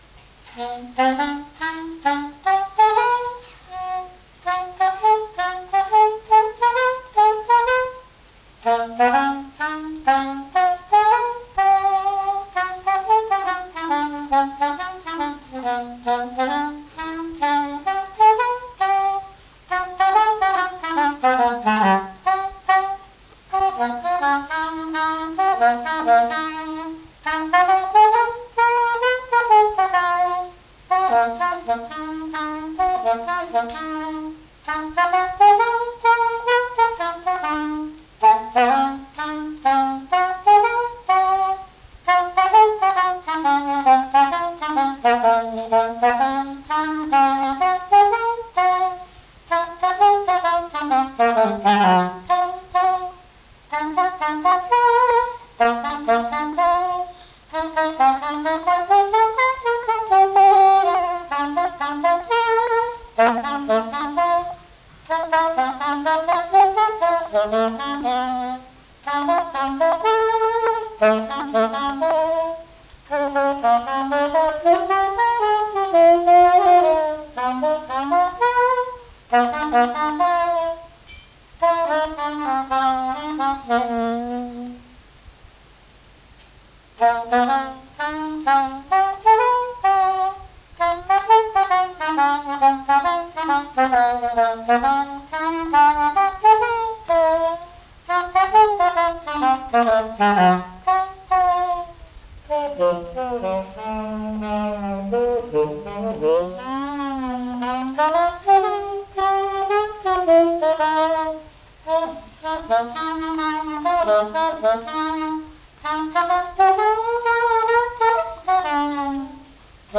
Scottish
Résumé instrumental
danse : scottish (autres)
Pièce musicale inédite